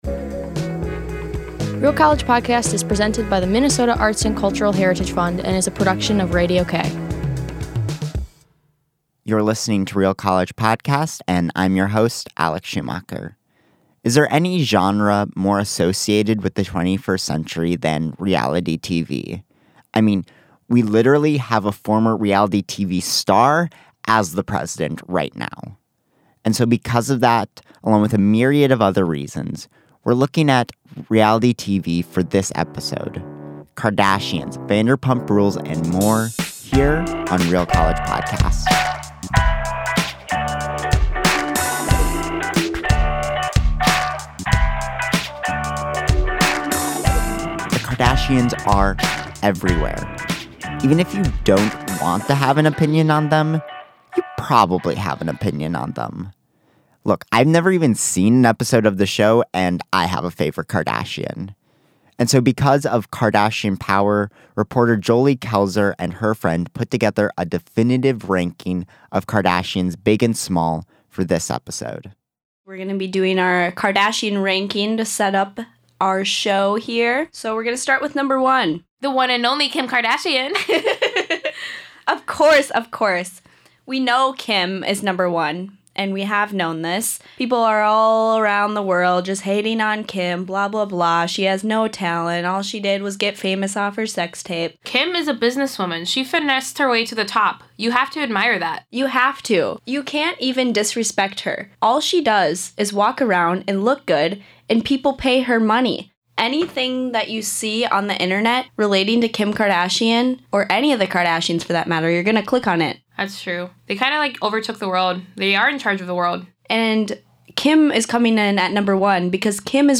Vanderpump Rules Interview